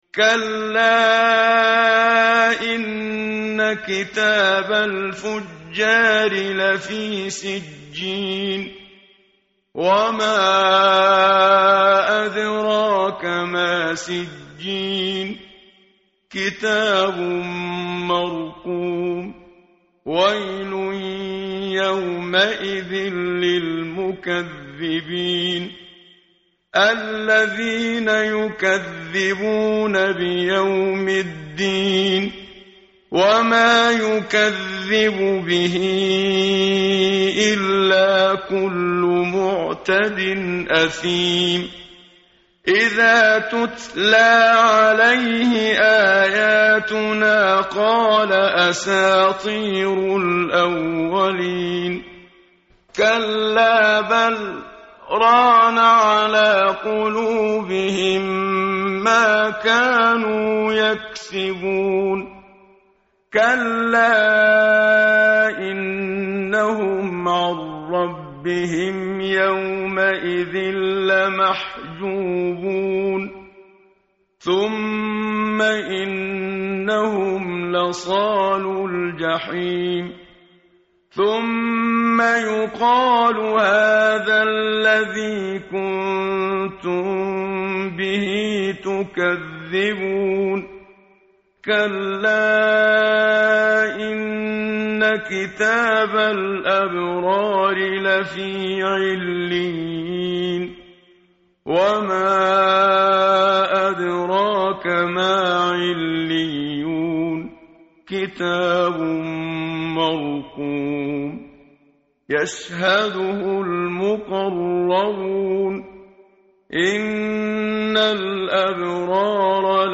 tartil_menshavi_page_588.mp3